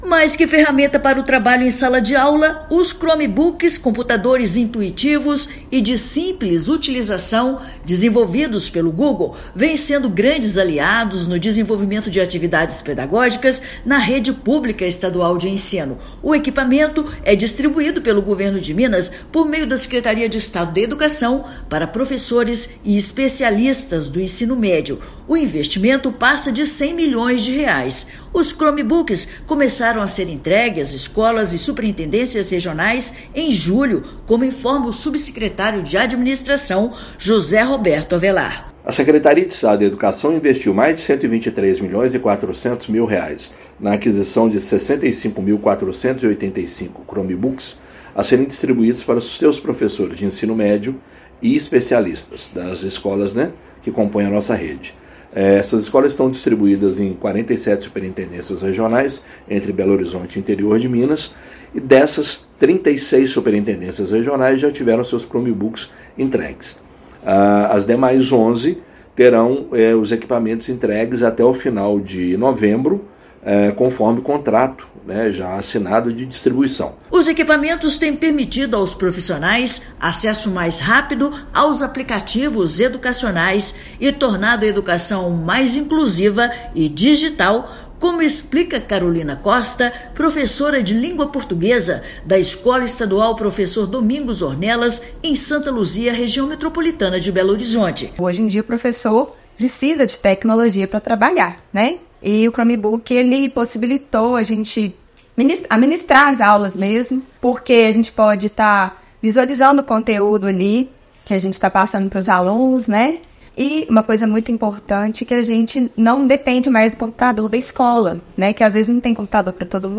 Cerca de 80% do total de 65 mil Chromebooks já foram enviados às escolas. A previsão é que todos os servidores contemplados recebam o equipamento até novembro. Ouça matéria de rádio.